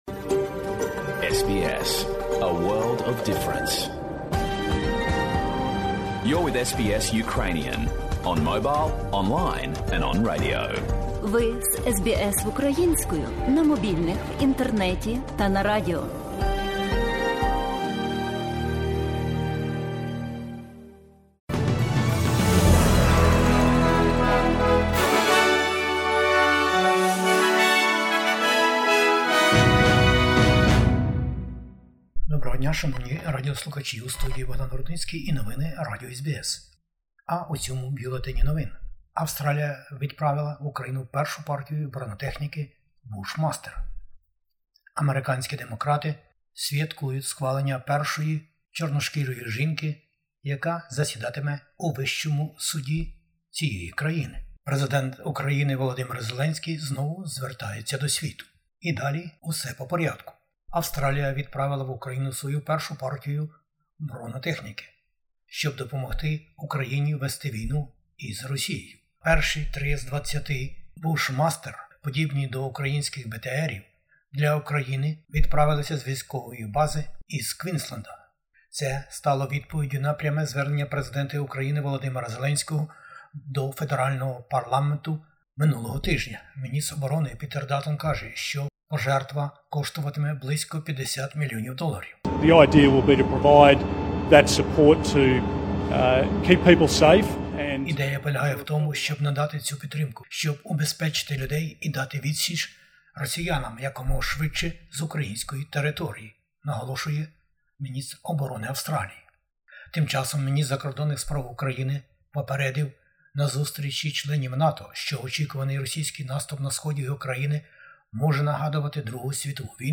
Бюлетень новин SBS українською мовою. Австралія відправила в Україну першу партію бронетехніки Bushmaster. Новий Прем'єр у Тасманії вже є. Федеральна опозиція про міґрацію. Федеральний уряд про захист країни і нову угоду щодо цього.